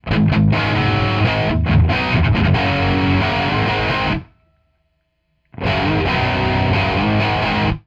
For all examples the guitar used is an SG with a Burstbucker 2 pickup in bridge position.
No settings were changed on the amp or guitar during the recordings and no processing or eq was applied to the tracks.
Because of this they tend to have a warmer sound that’s less harsh in the high frequencies than condenser mics.
MXL R144 street price $100
Ribbon-R144
Ribbon-R144.wav